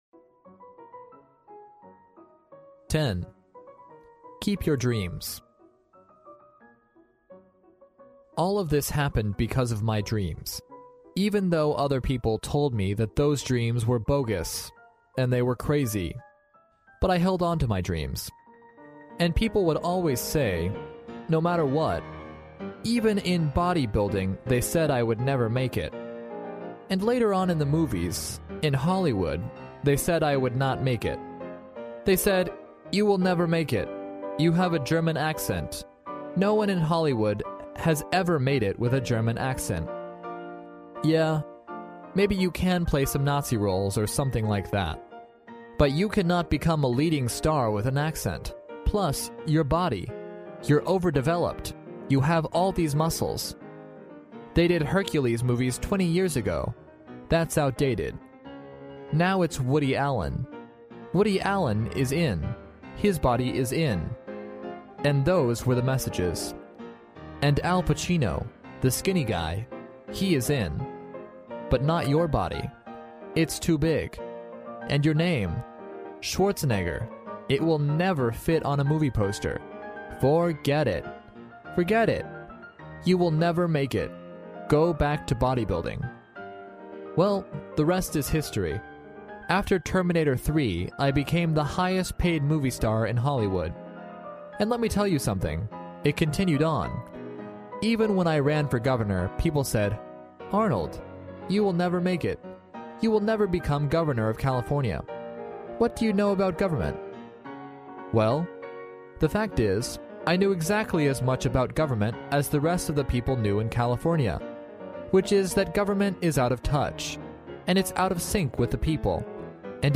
历史英雄名人演讲 第113期:阿诺德·施瓦辛格清华大学演讲:执着于你的梦想 听力文件下载—在线英语听力室